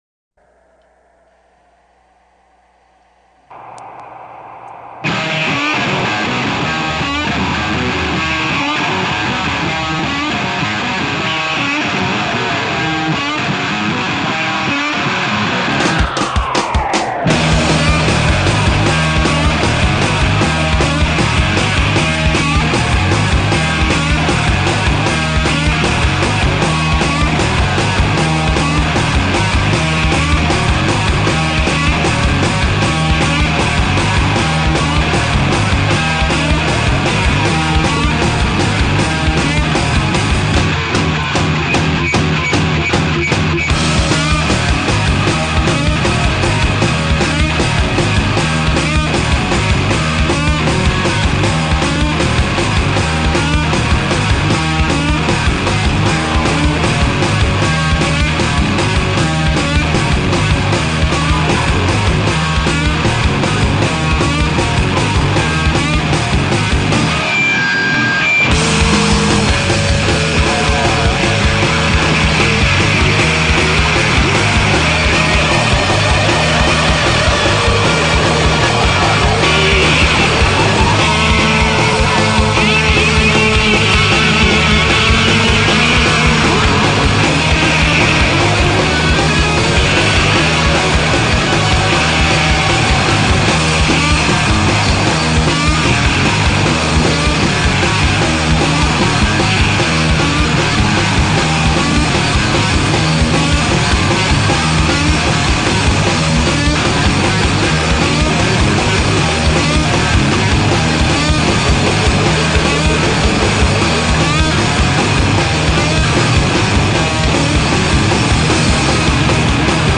Power pop laukotearen ohiko melodiak, gitarra zorrotz eta garbiekin.